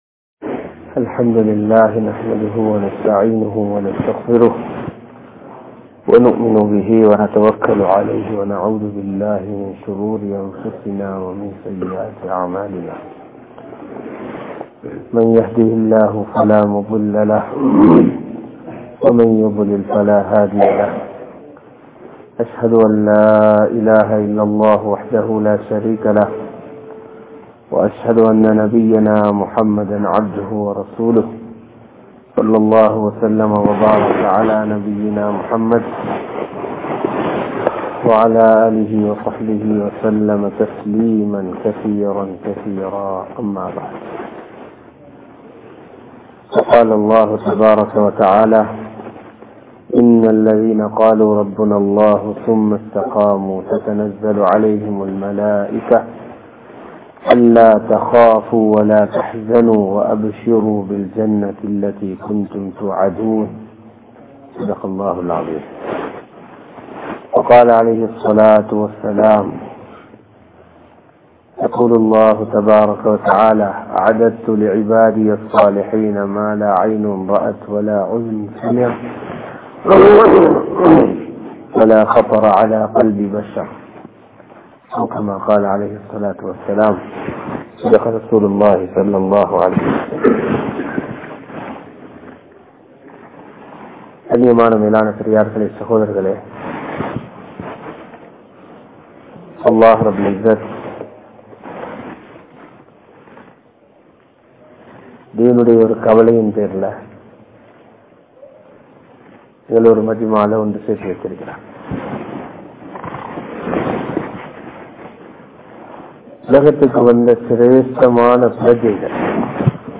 Allah`vai Nambugal (அல்லாஹ்வை நம்புங்கள்) | Audio Bayans | All Ceylon Muslim Youth Community | Addalaichenai